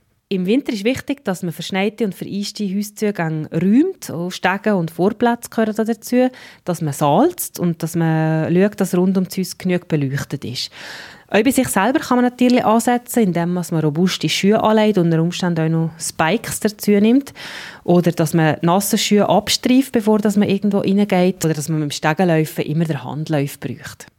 O-Ton zum Download